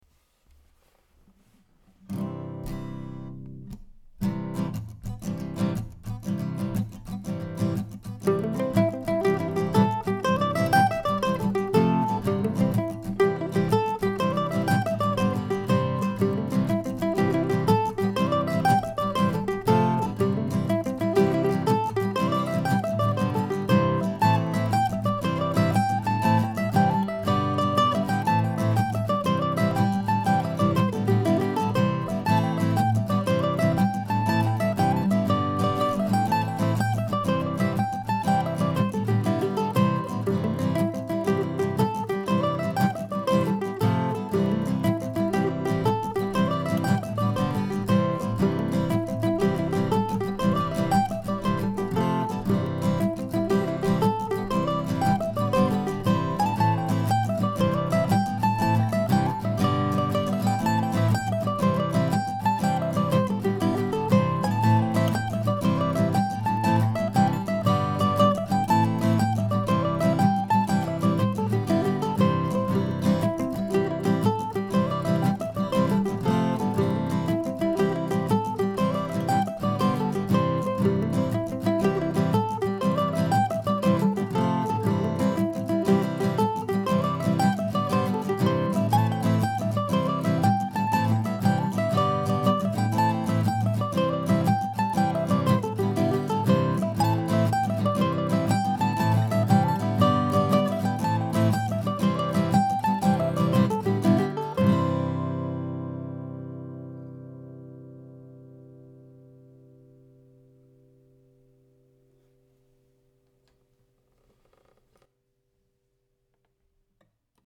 Here's a jig from a few weeks ago, recorded this morning.
Today's jig is squarely in the key of C, with nary a hint of Deer Tracks influence.